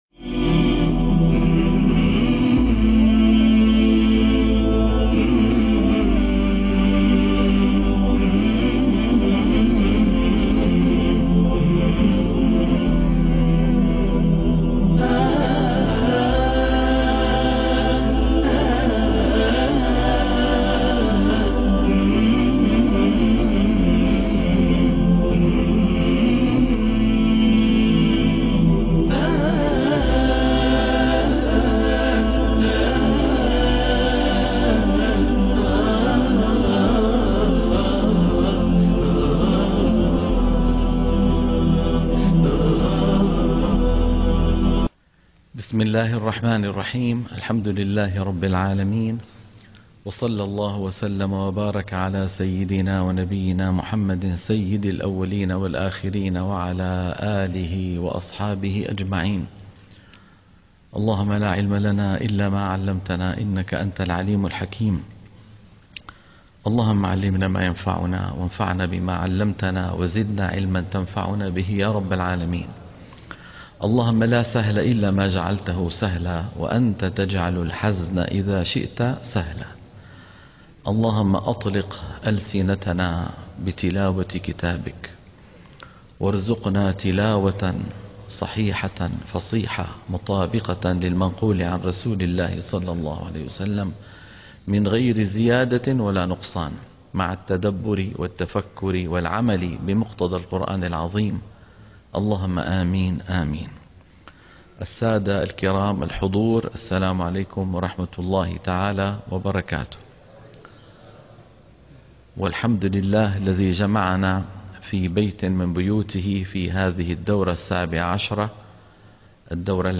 برنامج تصحيح التلاوة الحلقة - 101 - تصحيح التلاوة تلقين الصفحة 342